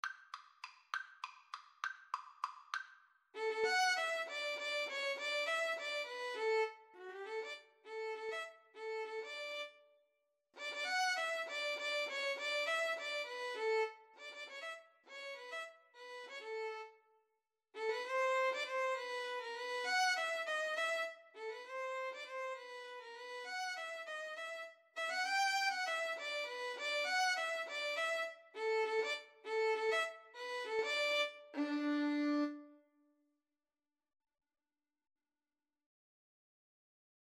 D major (Sounding Pitch) (View more D major Music for Violin Duet )
3/8 (View more 3/8 Music)
Classical (View more Classical Violin Duet Music)